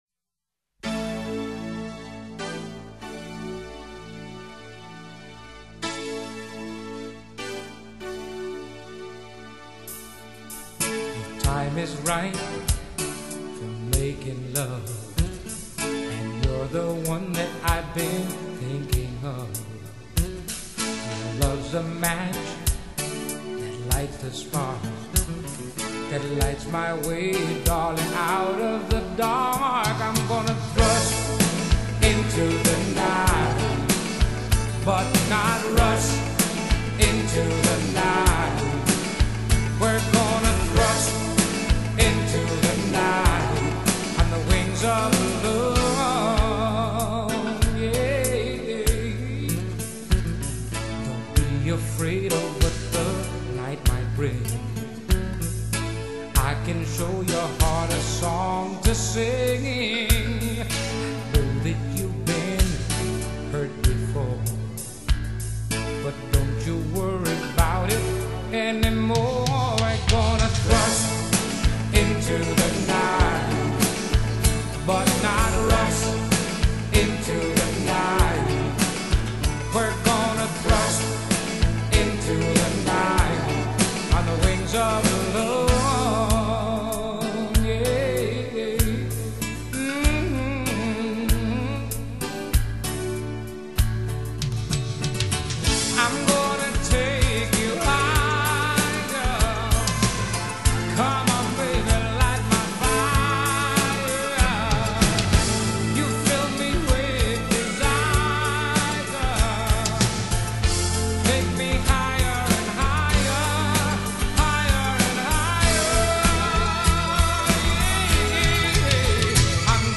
Genre: Latin Pop;Soft rock